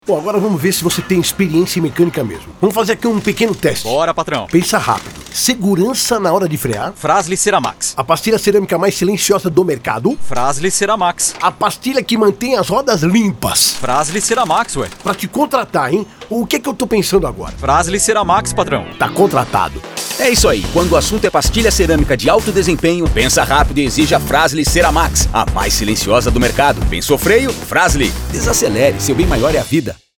spot-frasle-entrevista-2.mp3